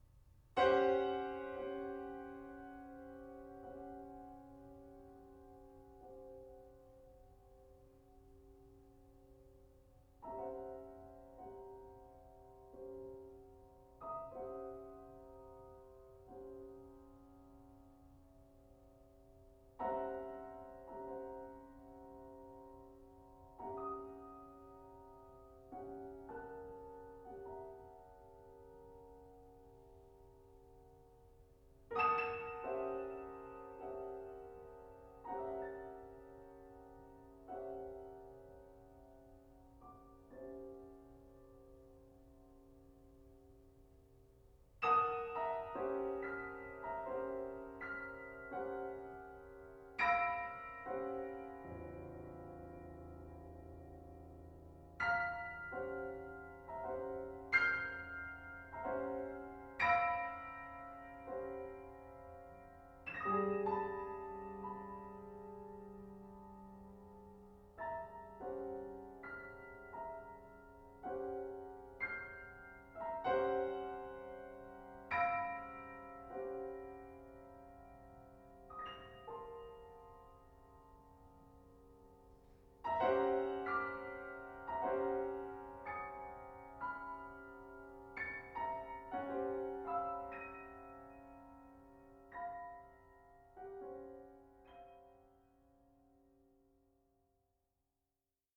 April 5th, 2024 ; Twelve Years in Six Scenes (2019) piano solo.